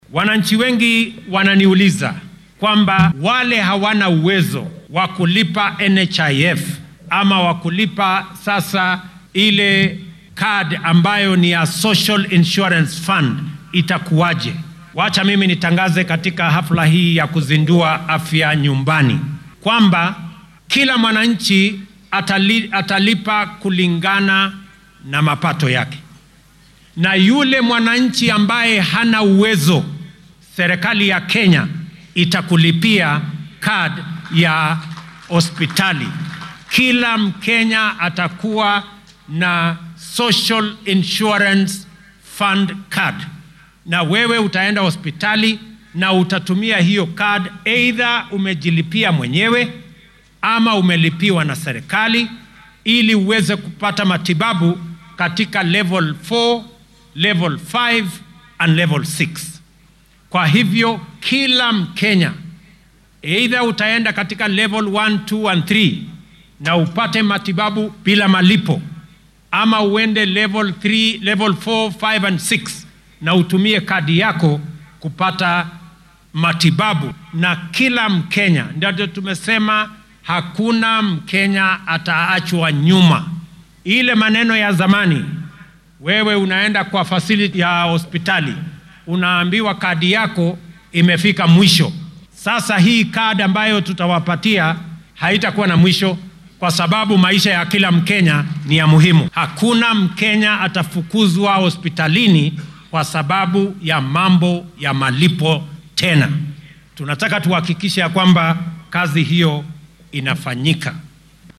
Madaxweynaha dalka William Ruto oo maanta khudbad ka jeedinayay munaasabadda xuska maalinta halyeeyada wadanka ee Mashujaa Day ayaa sheegay in dhammaan kenyaanka ay heli doonaan adeegyo caafimaad oo tayo leh, ay awoodaan sidoo kalena aan lagu kala takoorin.